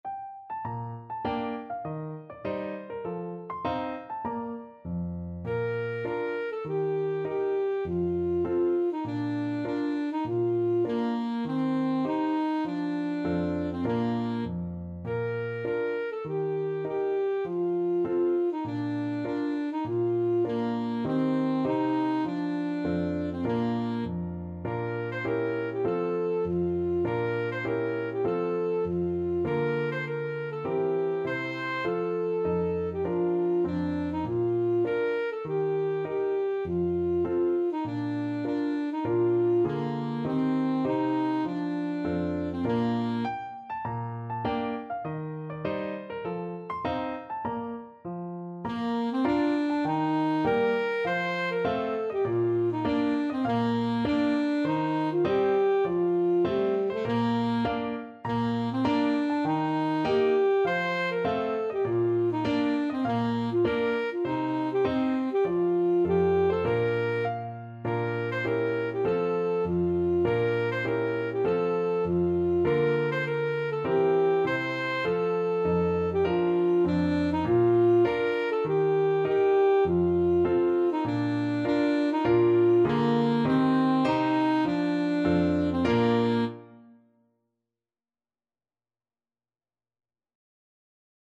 Alto Saxophone
4/4 (View more 4/4 Music)
G4-C6
Moderato
Traditional (View more Traditional Saxophone Music)